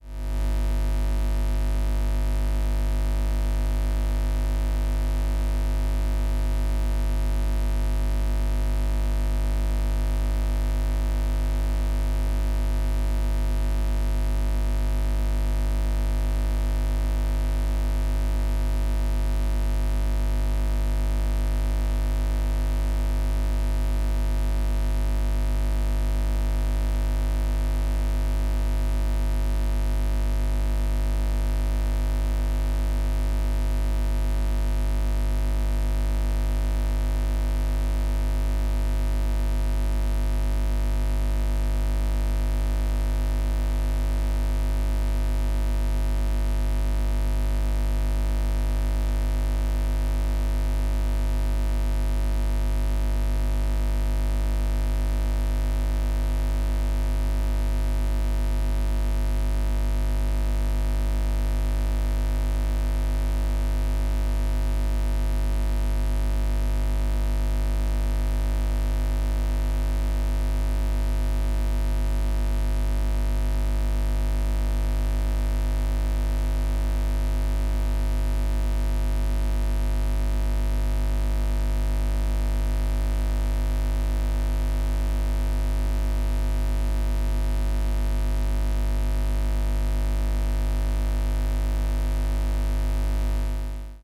电磁波录音 " Tascam 246 ( Buzz ) 3
描述：Tascam 246四轨录音机的感应线圈拾音器录音。
Tag: 科幻 实验 四轨 声景 感应线圈 无人驾驶飞机 4-轨道 黑暗 TASCAM 效果 抽象的 电磁的 FX的 电子的场记录 盒式磁带 噪声 磁带机